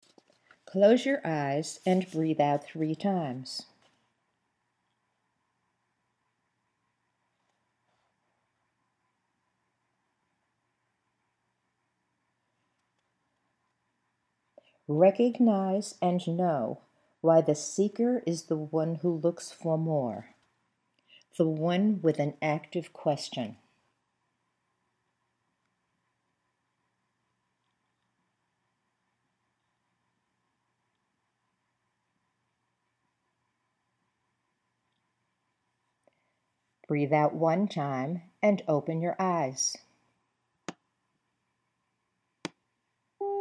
There are ten seconds of silence on the tape for your breathing, followed by the Imagery exercises.
You’ll notice silences on the audio after the breathing instructions, typically ten seconds for breathe out three times and fewer seconds for fewer breaths.